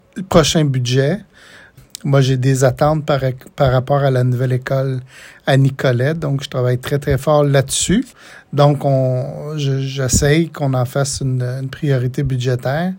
En entrevue, le député de Nicolet-Bécancour a mentionné qu’il travaillait toujours sur ce dossier.